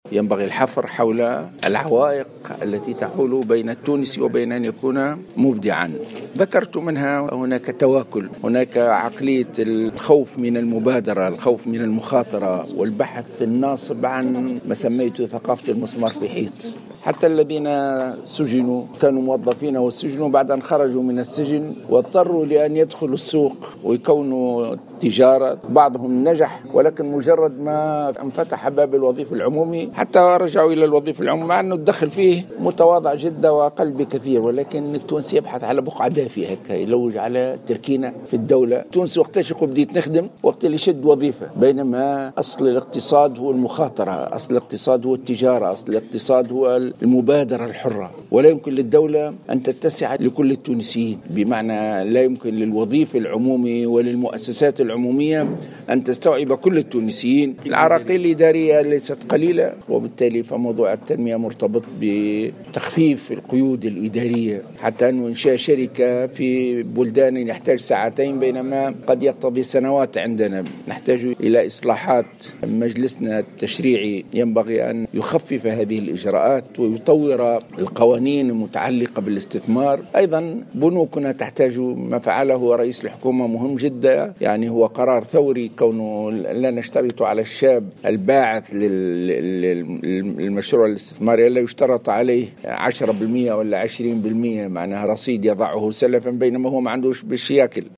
وقال في تصريحات صحفية على هامش ندوة نظمتها اليوم السبت جمعية نماء في نابل، إن أصل الاقتصاد هو المخاطرة والتجارة الحرة والمبادرة، مشيرا إلى ضرورة تخفيف القيود الإدارية في تونس للتشجيع على اطلاق المشاريع والاستثمار.